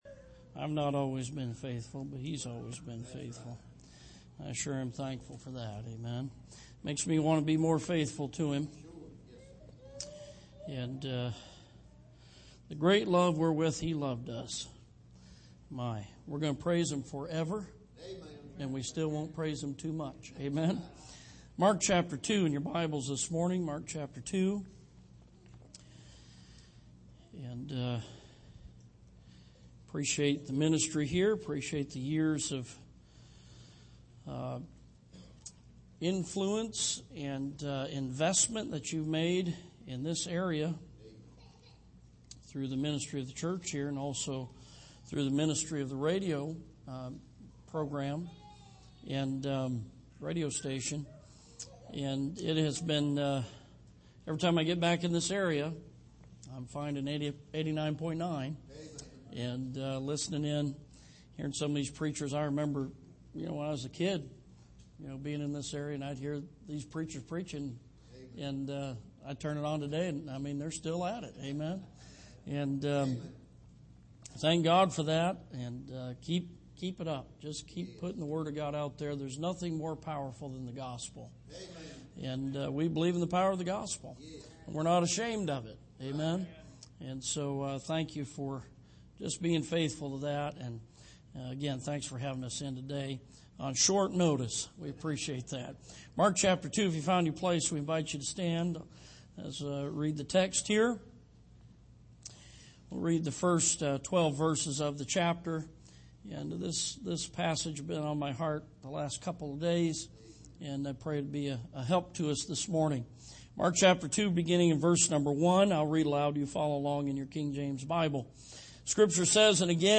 Sermon Archive
Here is an archive of messages preached at the Island Ford Baptist Church.